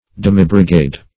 Demibrigade \Dem"i*bri*gade"\, n.
demibrigade.mp3